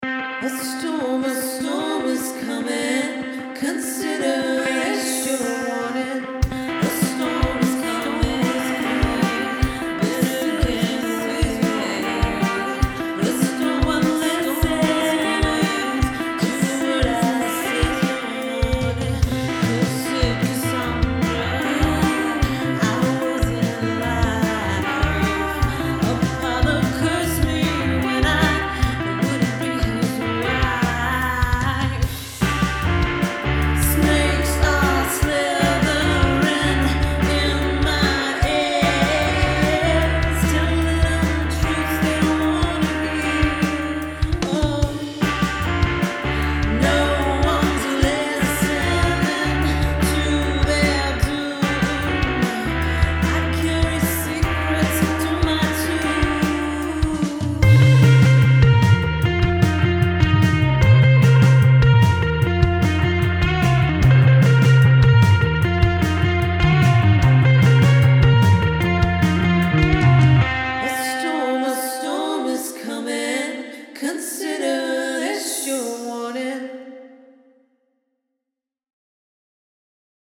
Liars & Believers transforms ancient, divine justice into contemporary, thrashing vengeance – in a new theatrical adaptation with driving text, kinetic physicality, and a Riot-Grrrl punk band.
These are the first demo recordings